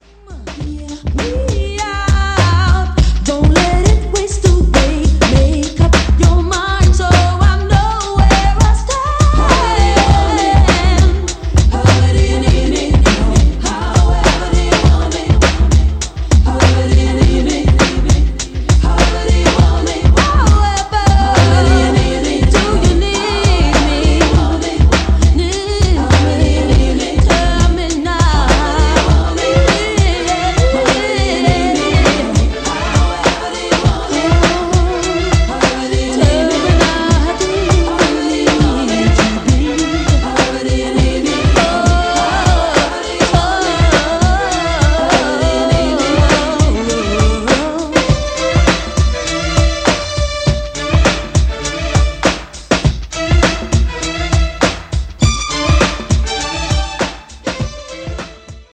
The Model 5000 just looks beautiful it also does sound amazing.
Below is a test recording made with the Model 5000 and played back by it: